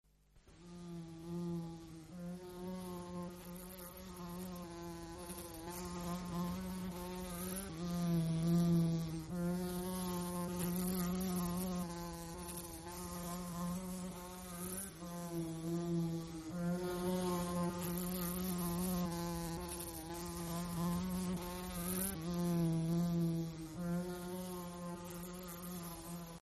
دانلود آهنگ مگس 2 از افکت صوتی انسان و موجودات زنده
دانلود صدای مگس 2 از ساعد نیوز با لینک مستقیم و کیفیت بالا
جلوه های صوتی